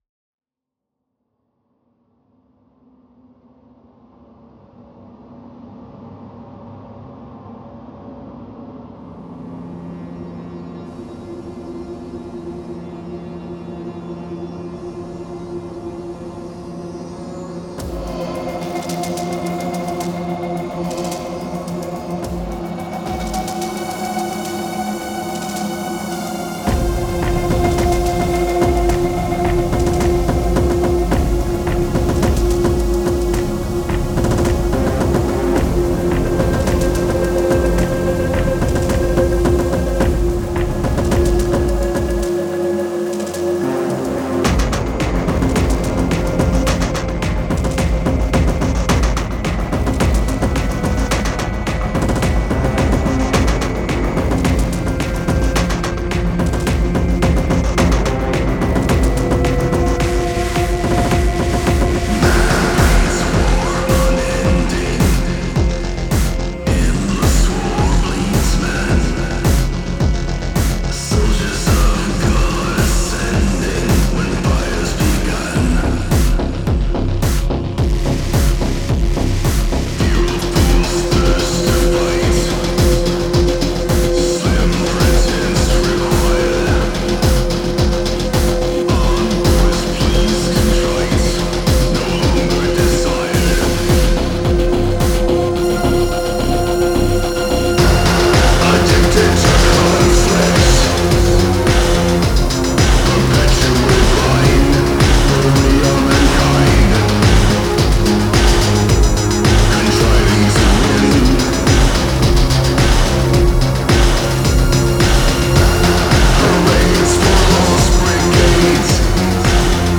EBM, Industrial, Dark Ambient, Rhythmic Noise